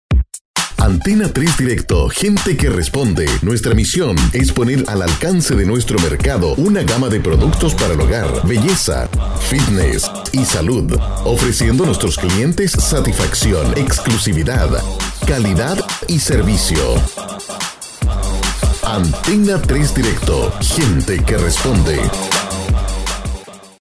LOCUTOR PROFESIONAL MAS DE 25 AÑOS DE EXPERIENCIA EN ESTACIONES DE RADIO Y AGENCIAS, ADEMAS ACTOR DOBLAJISTA.(VOICEOVER)
spanisch Südamerika
chilenisch
Sprechprobe: Werbung (Muttersprache):